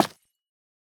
Minecraft Version Minecraft Version snapshot Latest Release | Latest Snapshot snapshot / assets / minecraft / sounds / block / calcite / break3.ogg Compare With Compare With Latest Release | Latest Snapshot